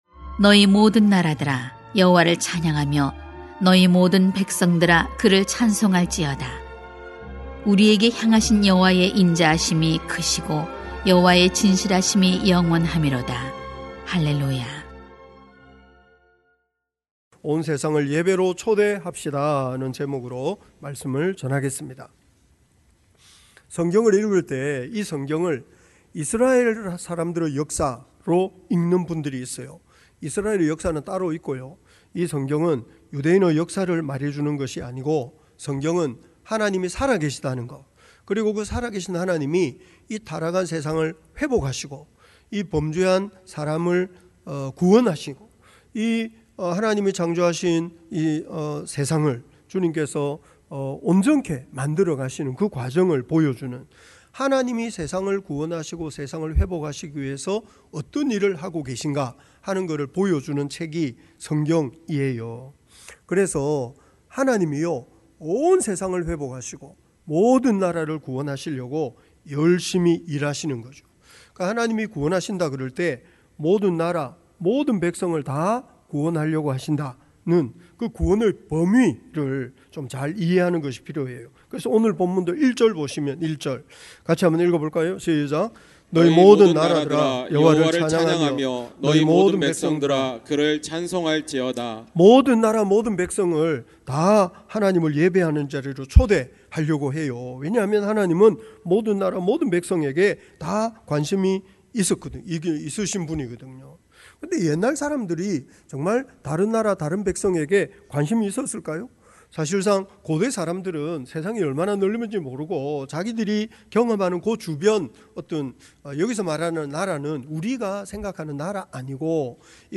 [시편 117] 온 세상을 예배로 초대합시다 > 주일 예배 | 전주제자교회